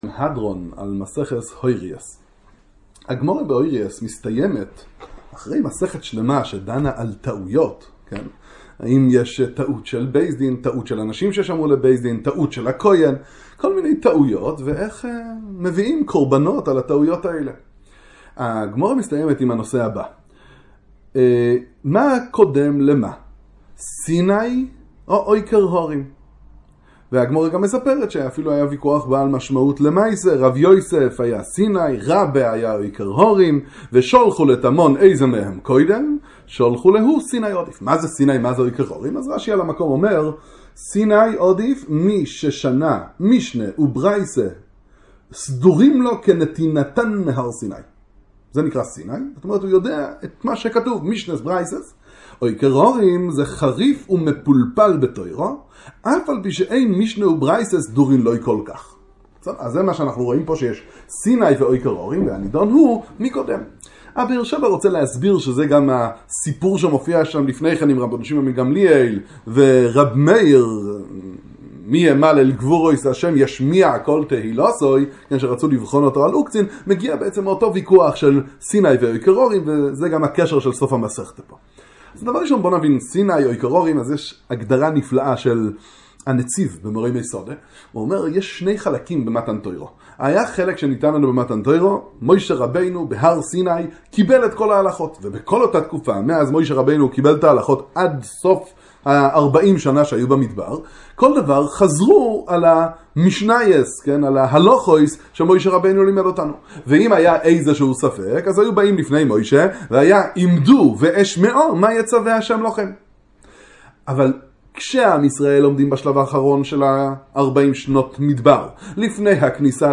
דרשה לסיום מסכת הוריות